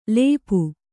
♪ lēpu